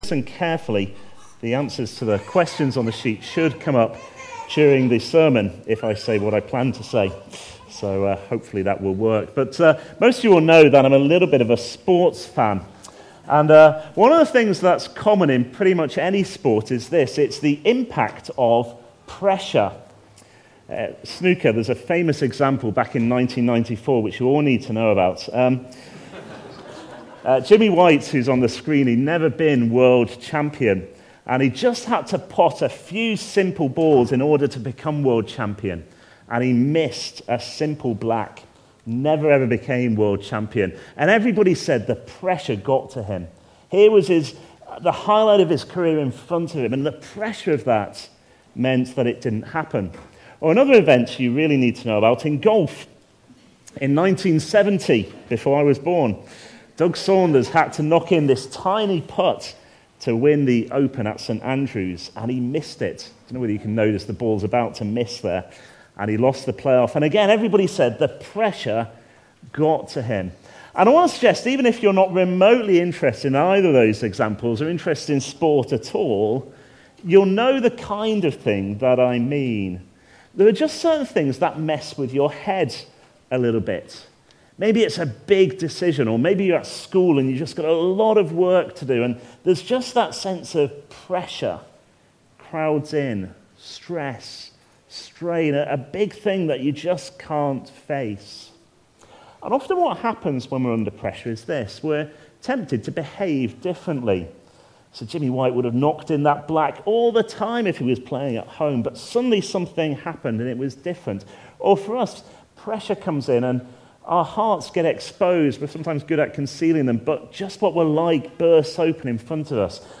Confidence (Psalm 27:11-14) from the series A Vision for 2015. Recorded at Woodstock Road Baptist Church on 25 January 2015.